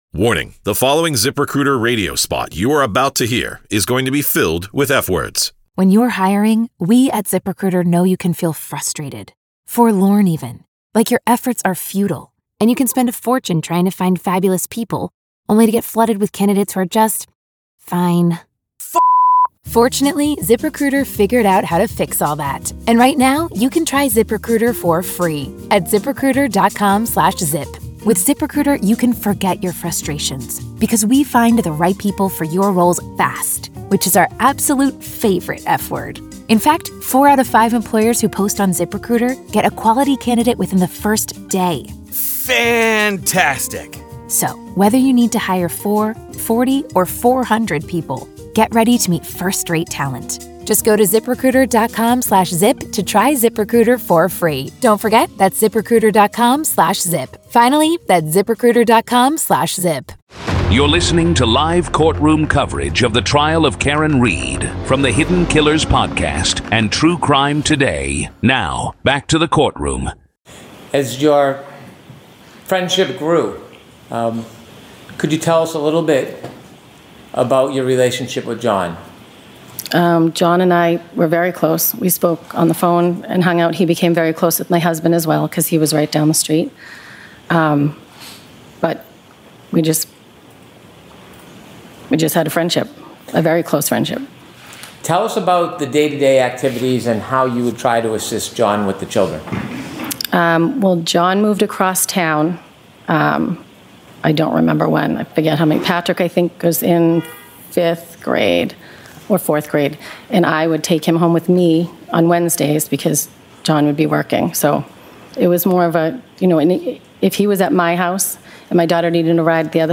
This is live audio from the courtroom in the high-profile murder retrial of Karen Read in Dedham, Massachusetts.